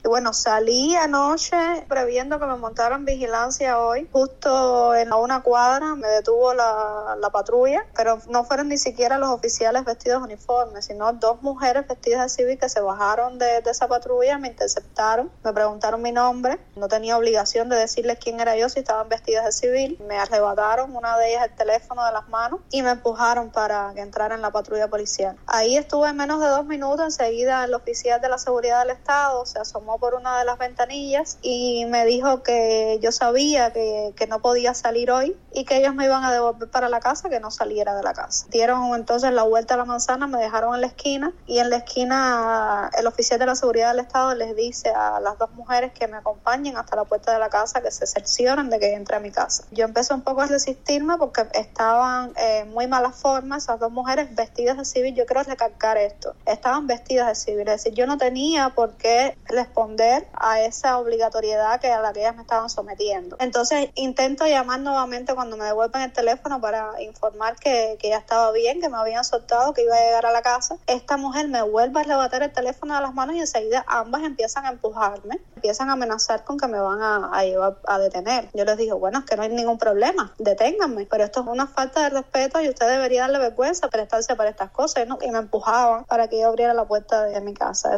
en conversación con Radio Marti